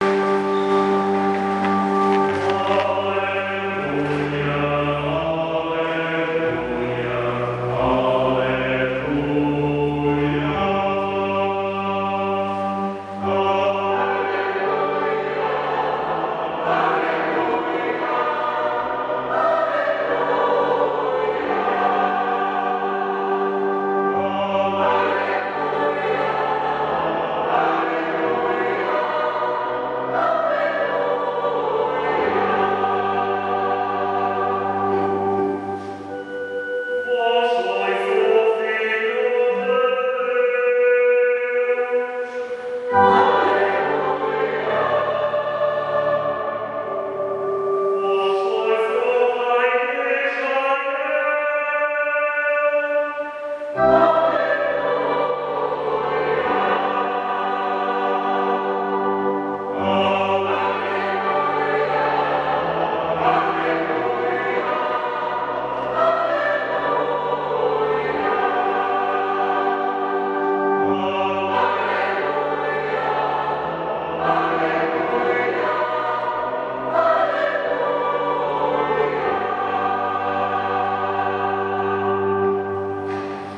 Grupo Coral de Veiros na Eucaristia de 24 de Agosto de 2020
├uudio-3-Aleluia.mp3